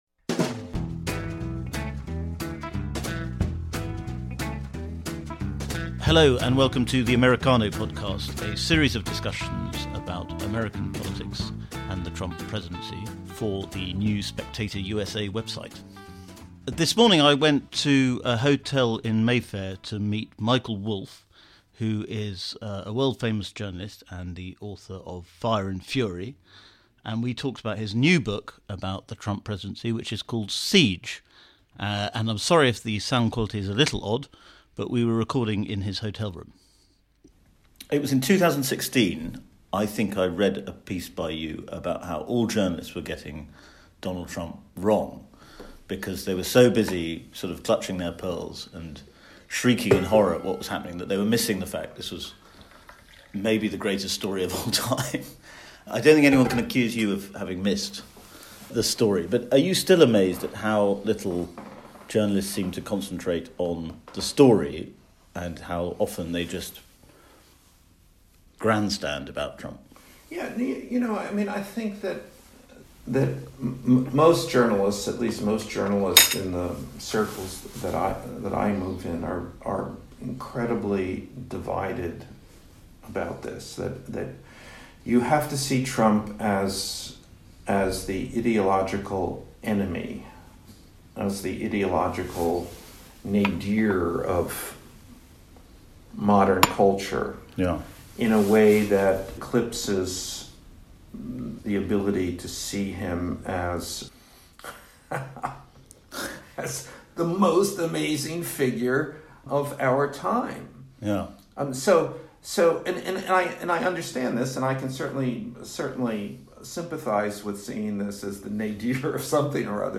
Americano is a series of in-depth discussions on American politics with the best pundits stateside.
And I'm sorry if the sound quality is a little odd, but we were recording in his hotel room.